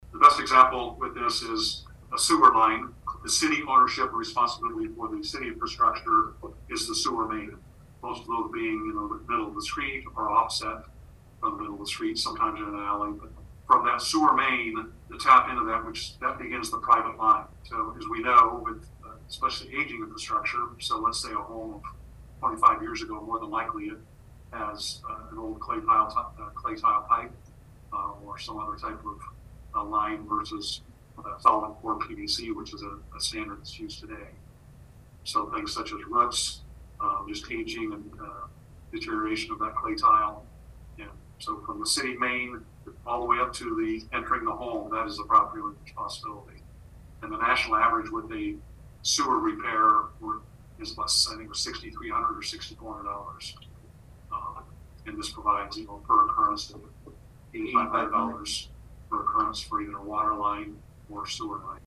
Gettys provided an example of how this program would work.